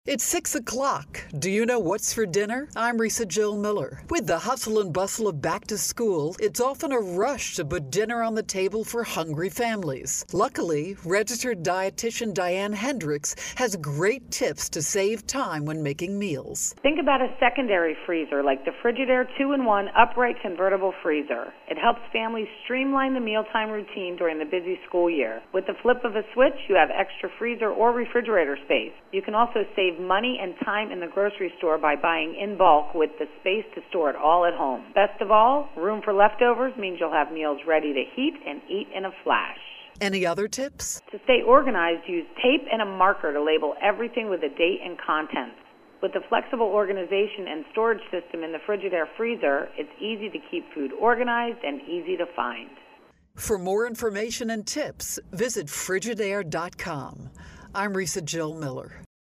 August 20, 2013Posted in: Audio News Release